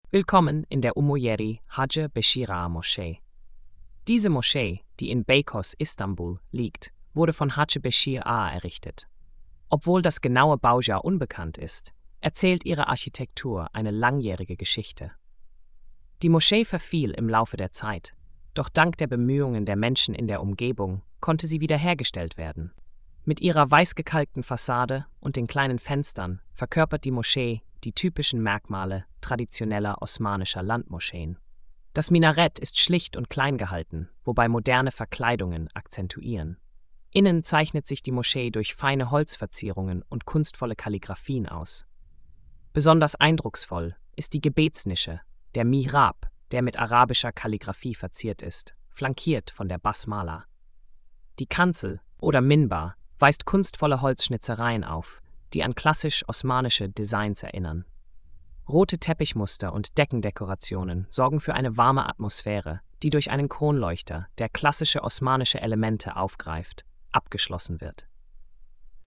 Audio Erzählung